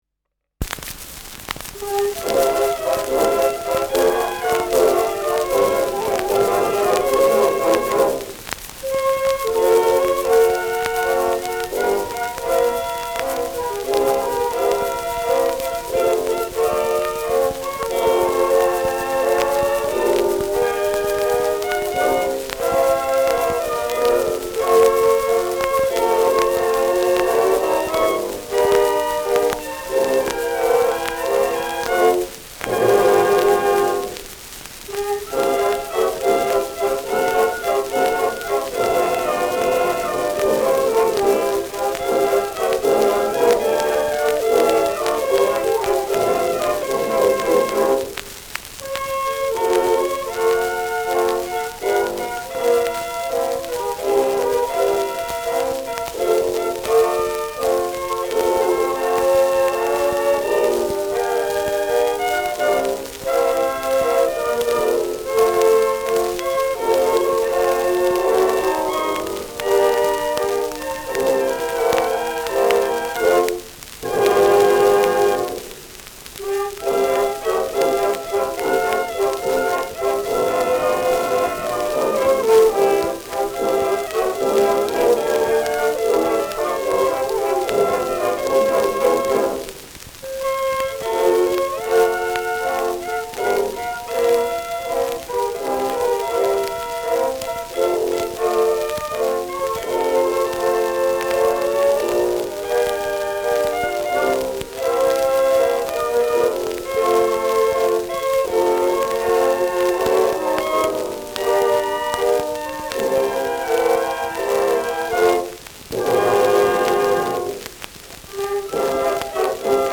Schellackplatte
präsentes Rauschen : Nadelgeräusch : Knacken zu Beginn : präsentes Knistern : abgespielt : gelegentliches Knacken : leiert
Seidler's Orchester (Interpretation)